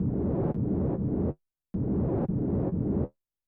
DANGER NOI-L.wav